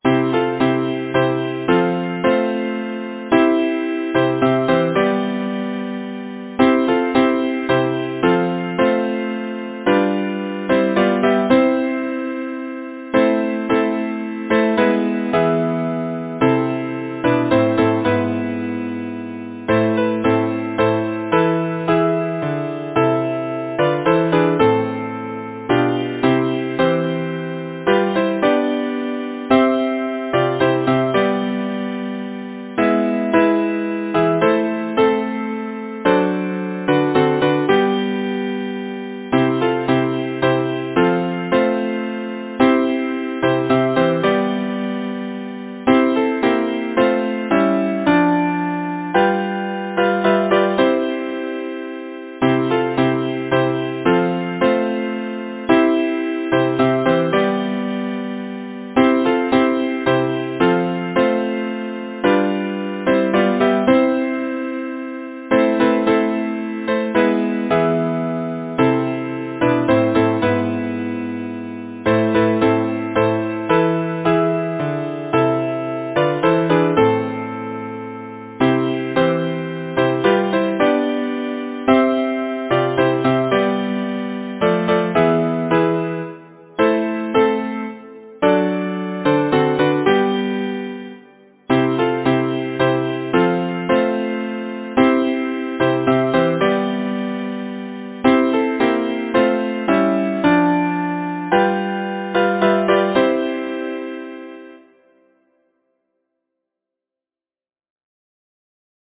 Title: Sweet Spring is coming Composer: Wm. T. Belcher Lyricist: George Linnæus Banks Number of voices: 4vv Voicing: SATB Genre: Secular, Partsong
Language: English Instruments: A cappella